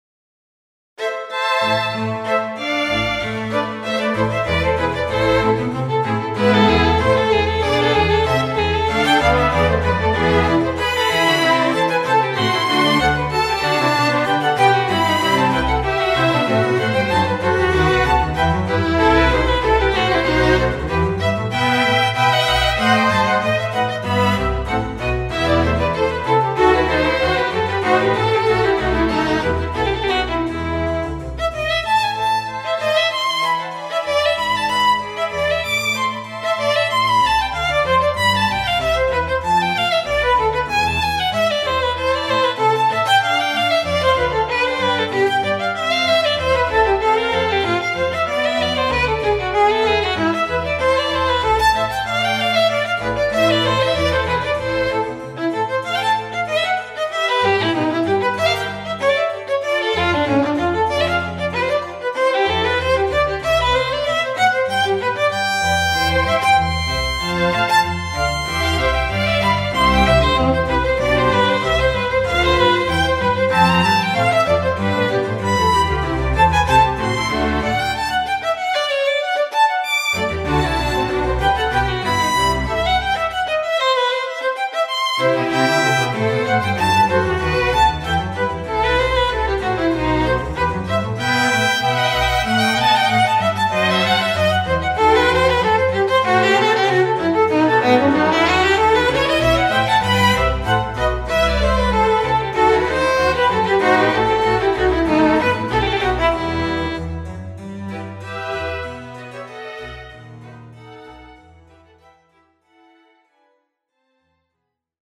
I've updatet my BWV 1041 with the solo violin as well for a better comparison.
So there are still some "hicks" which should be improved and the piece will fade out...
Full version: all Strings, solo violin and cembalo
Front: Solo Violin / Behind it: Viols1-Vliolas-Cellos-CembaloViols2